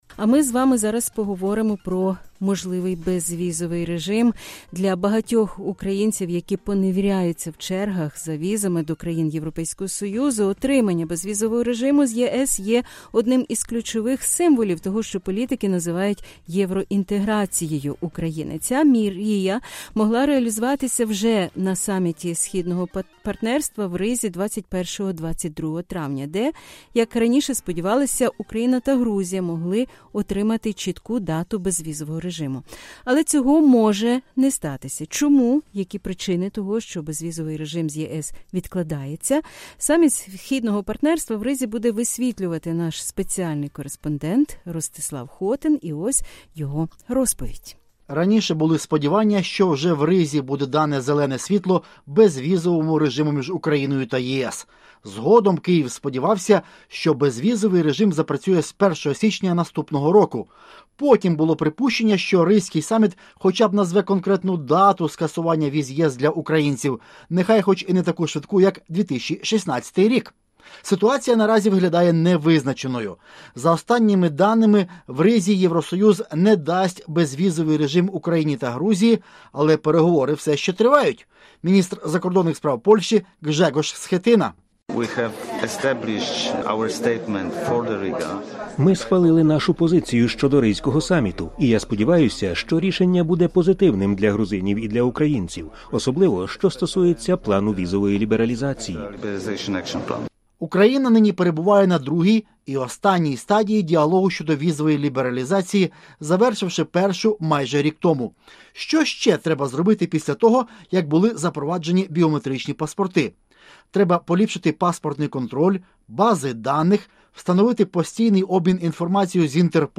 В ексклюзивному інтерв’ю Радіо Свобода в Ризі прем’єр Латвії сказала, що наразі ще тривають консультації між 28 країнами-членами ЄС та шістьма країнами «Східного партнерства» щодо проекту декларації саміту, який відбудеться 21-22 травня.